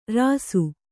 ♪ rāsu